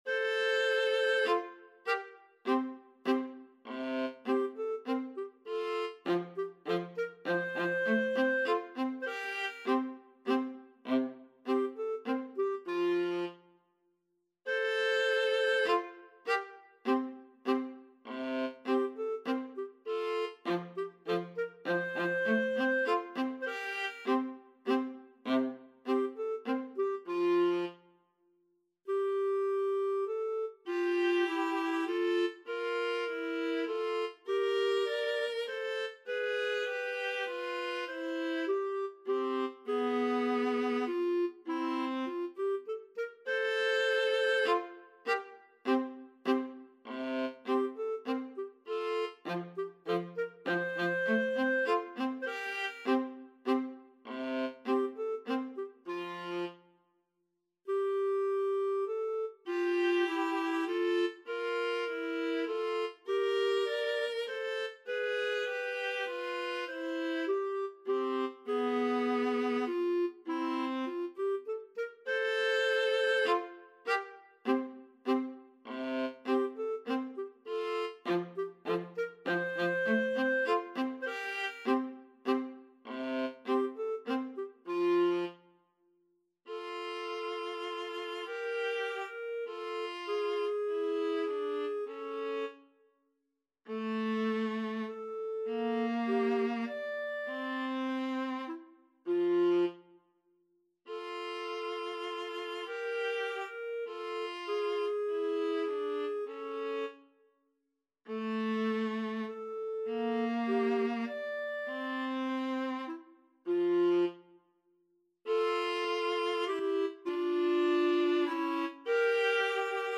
Allegretto - Menuetto
3/4 (View more 3/4 Music)
Classical (View more Classical Clarinet-Viola Duet Music)